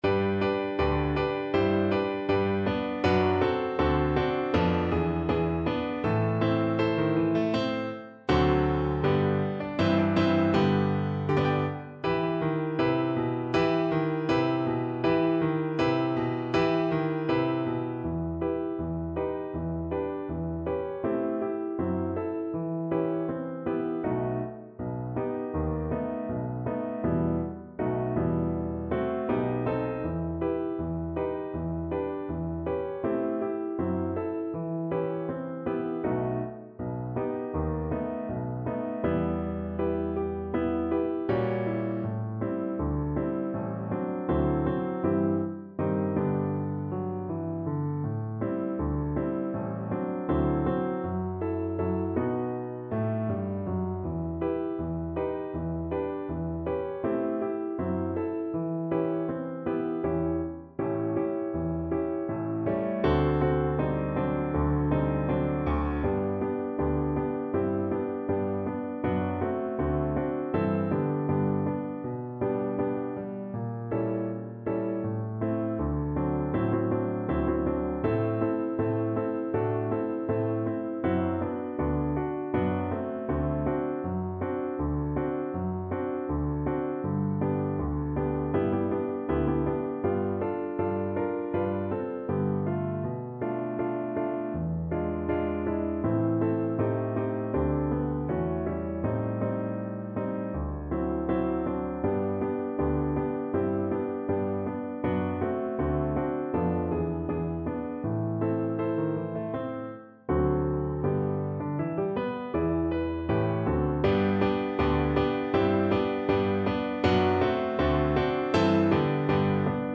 Cello version
2/2 (View more 2/2 Music)
~ = 160 Moderato
Pop (View more Pop Cello Music)